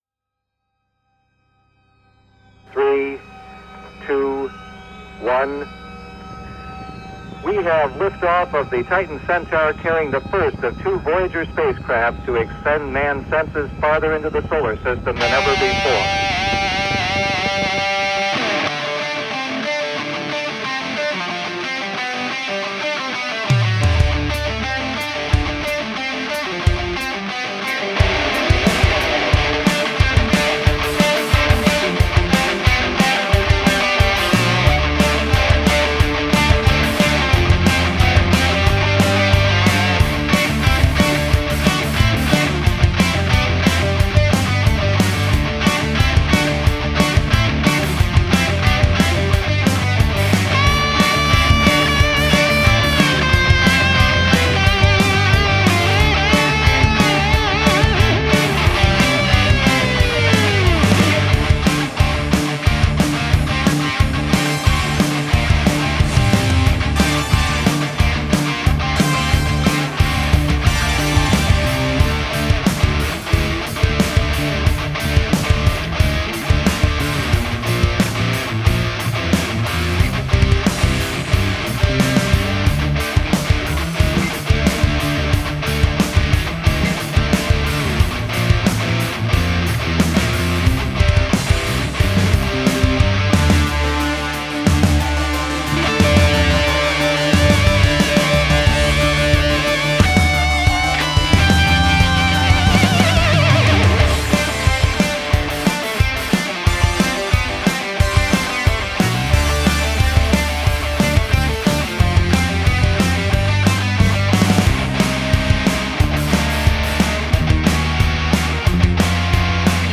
Voyager - Instrumental guitar shredding